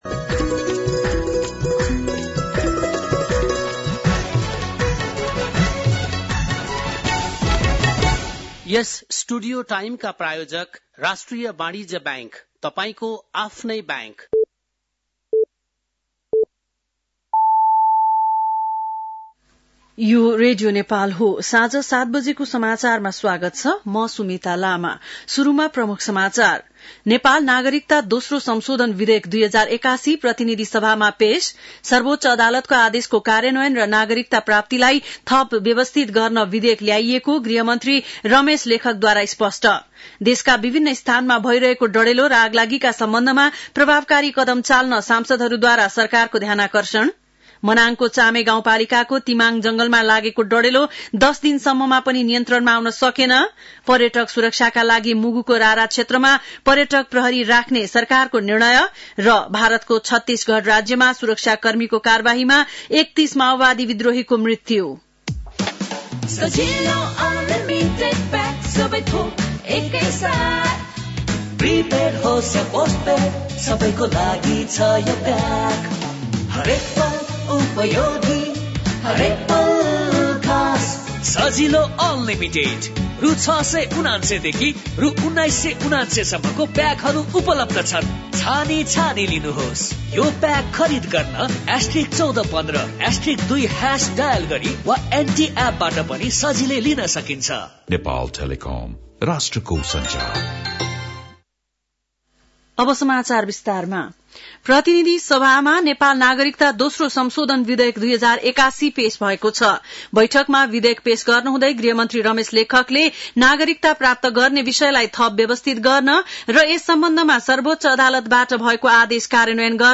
बेलुकी ७ बजेको नेपाली समाचार : २८ माघ , २०८१
7-pm-news-1.mp3